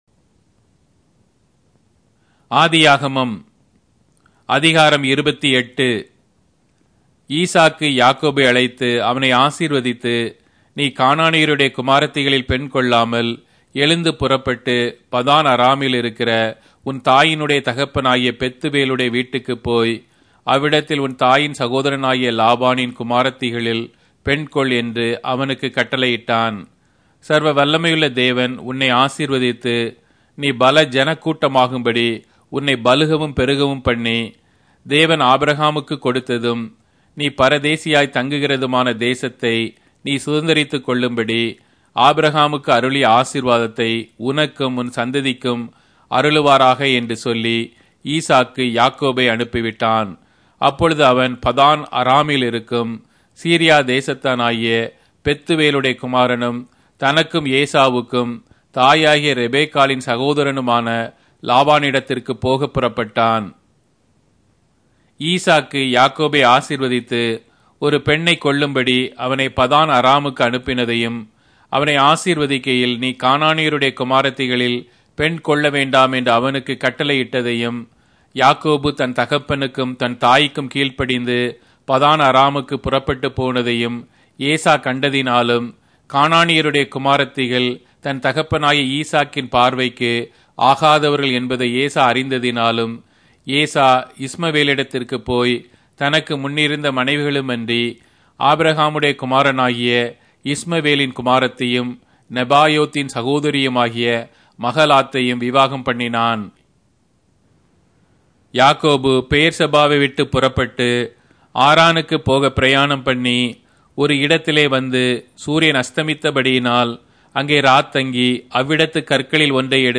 Tamil Audio Bible - Genesis 28 in Pav bible version